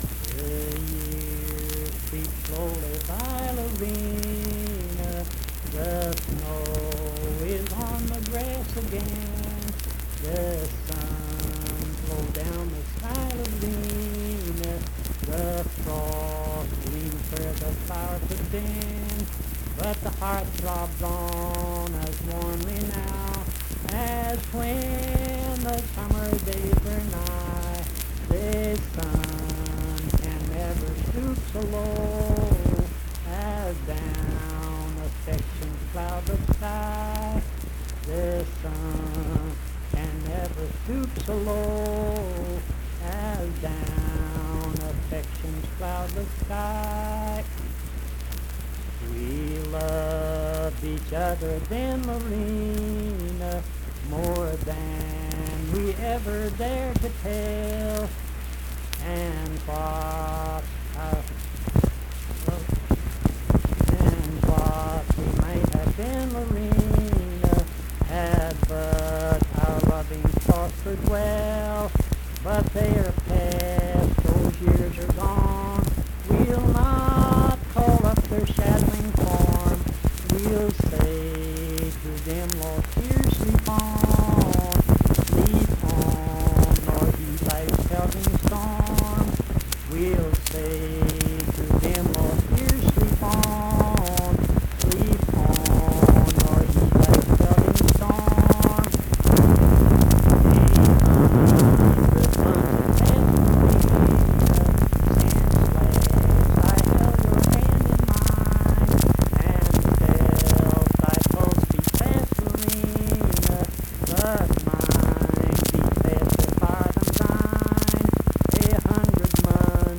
guitar
Verse-refrain 4(10w/R).
Performed in Mount Harmony, Marion County, WV.
Voice (sung)